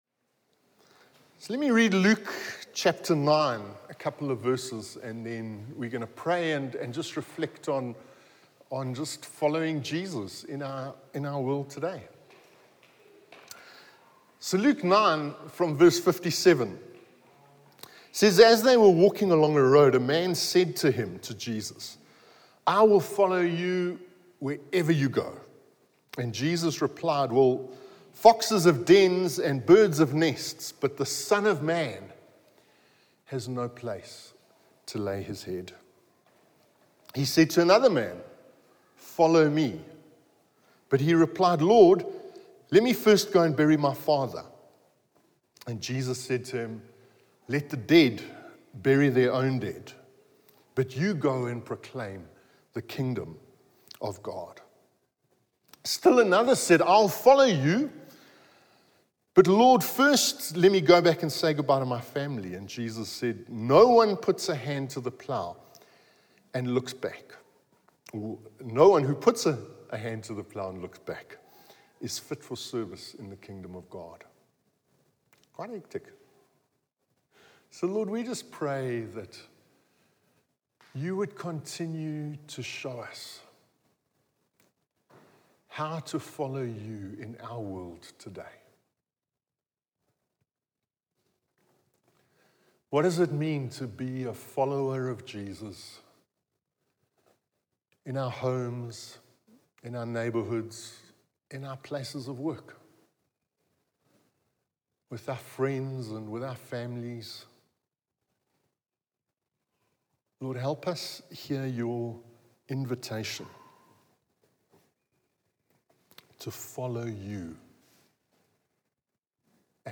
From Hillside Vineyard Christian Fellowship, at Aan-Die-Berg Gemeente. Johannesburg, South Africa.